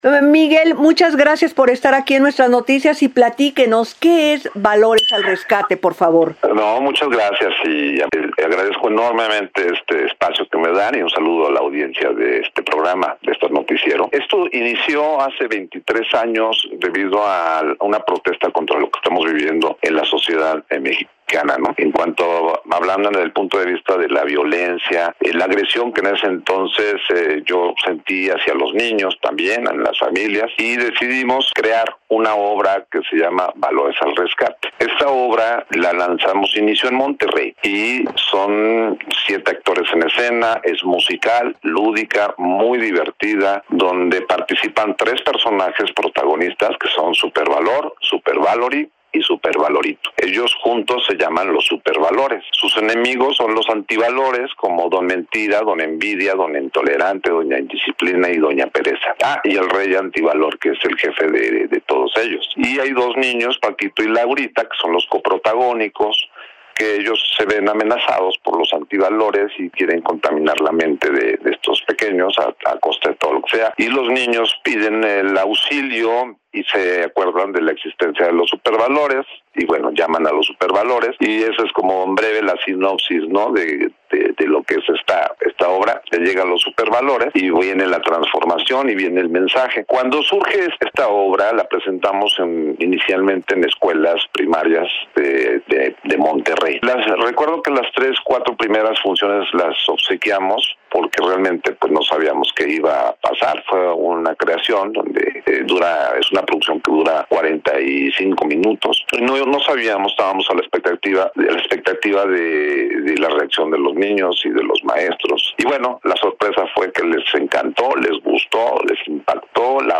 04-ENTREV-VALORES-17-ENERO.mp3